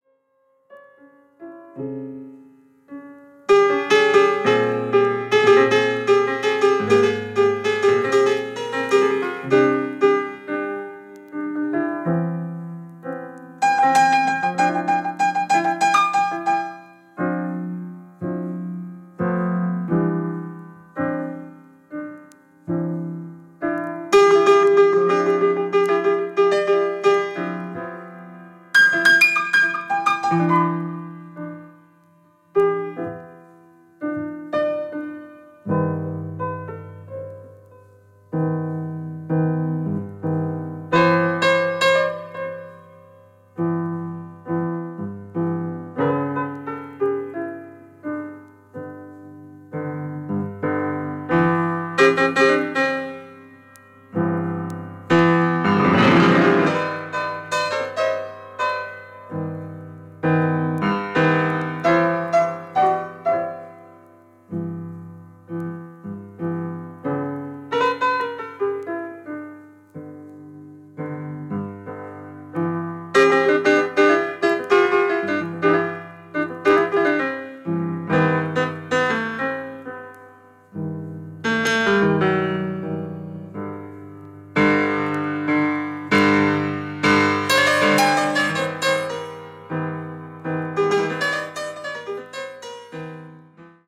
カナダ鍵盤奏者